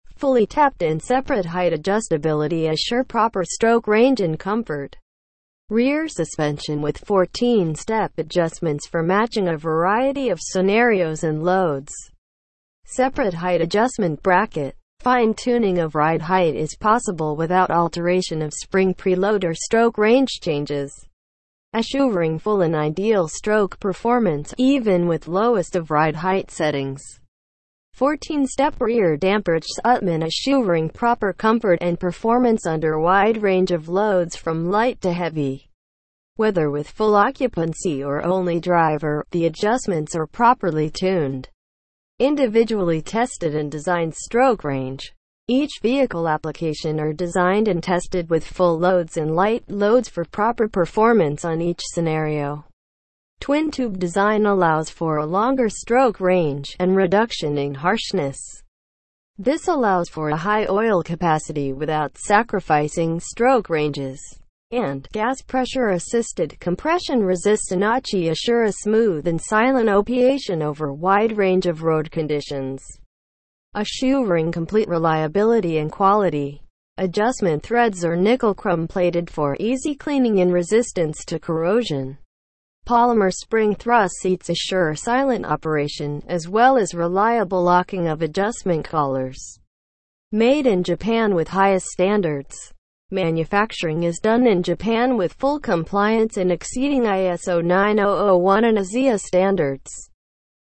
TEXT TO SPEECH